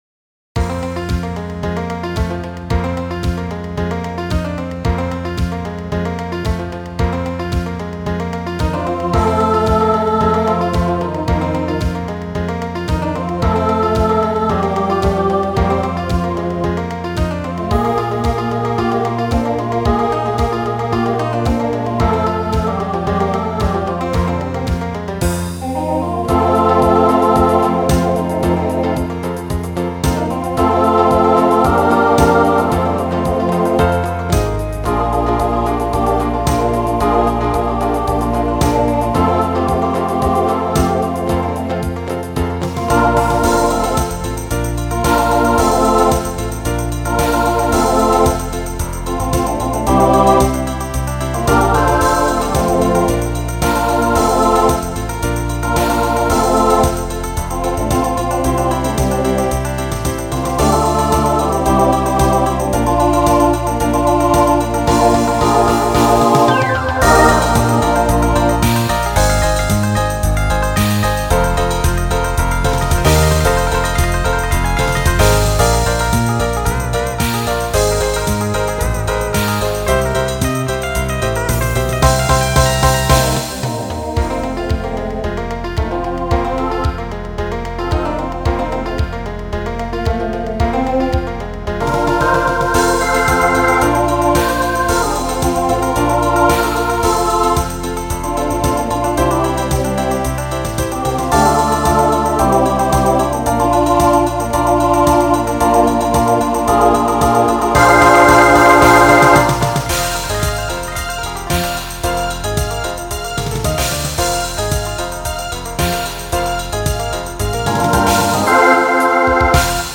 Voicing SATB Instrumental combo Genre Country